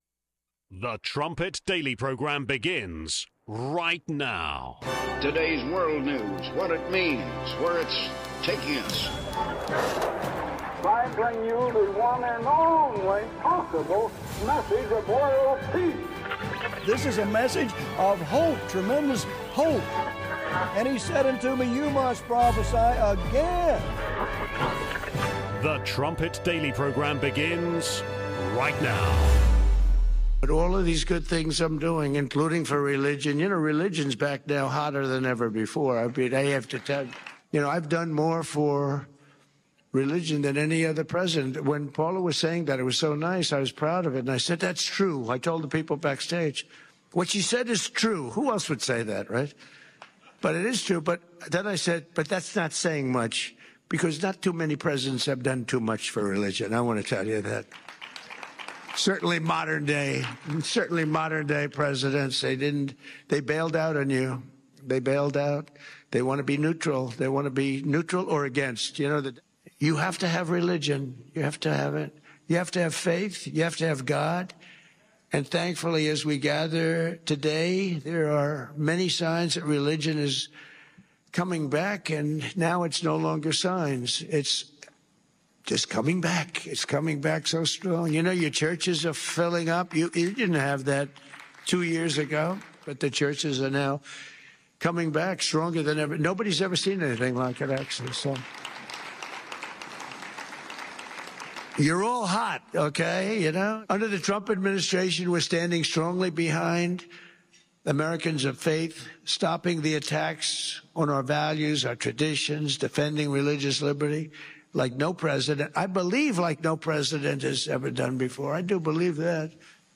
14:45 Trumpet Daily Interview: Gadi Taub, Part 3 (39 minutes)